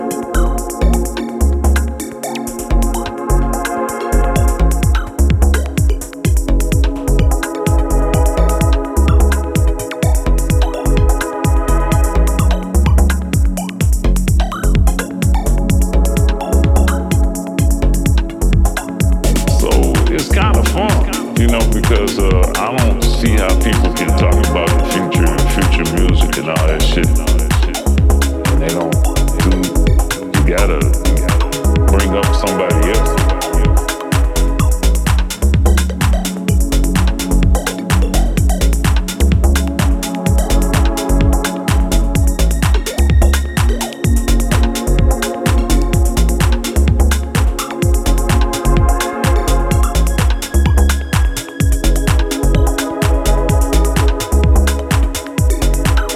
Warmth in electronics through the machines…
Deep house